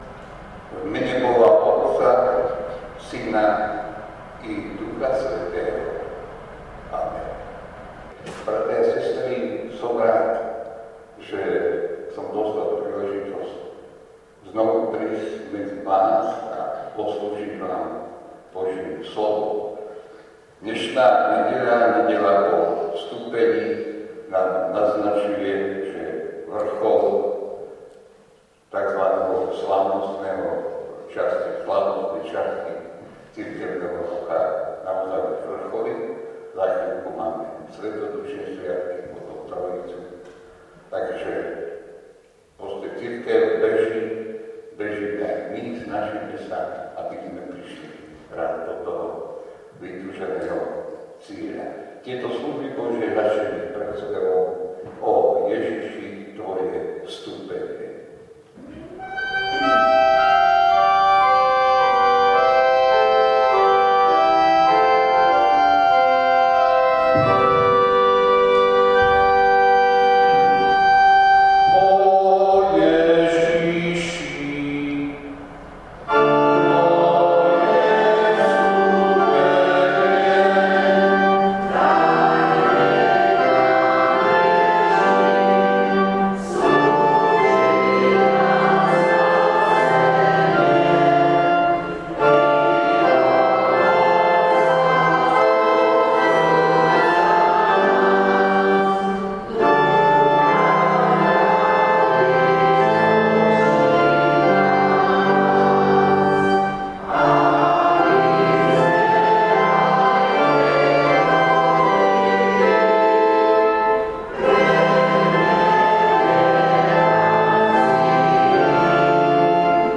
Služby Božie – Nedeľa po Vstúpení
V nasledovnom článku si môžete vypočuť zvukový záznam zo služieb Božích – Nedeľa po Vstúpení.